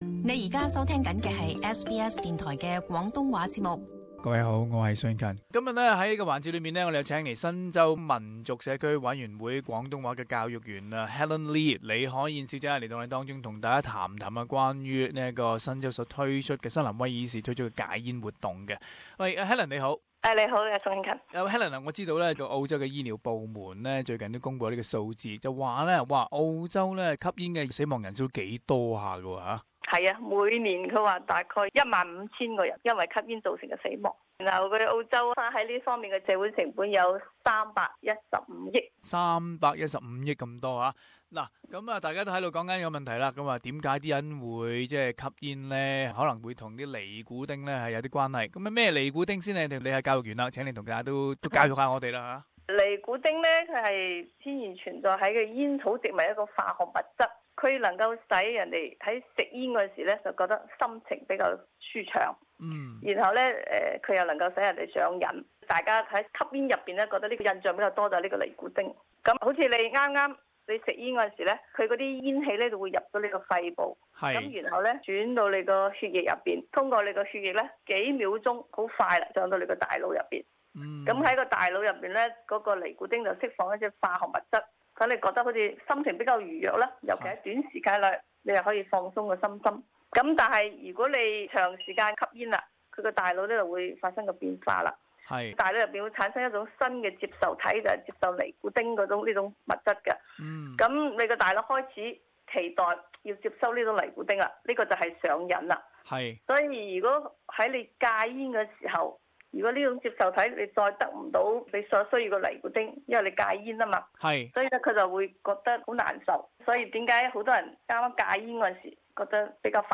【社團專訪】新州民族社區委員會推出禁煙宣傳計劃